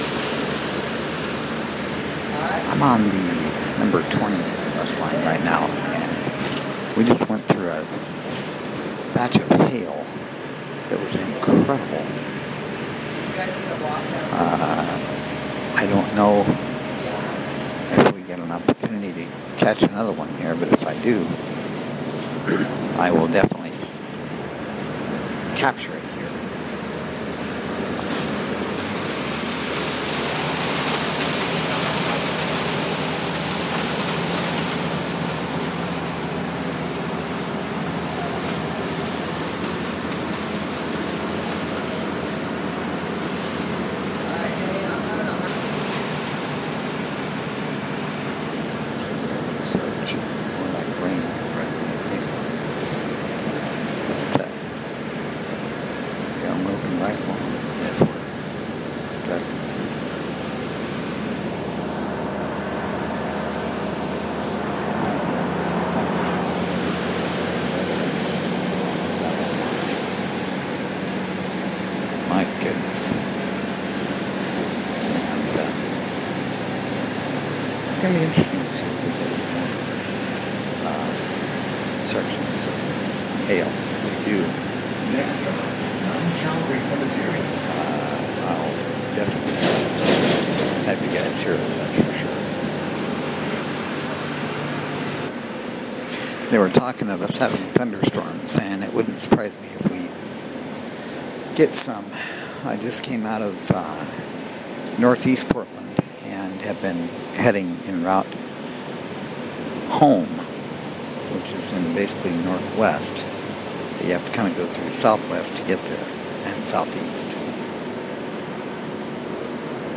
join me, as I'm on my way home and catch a section of hail coming down on the bus.
It was piece sized plus and it was loud.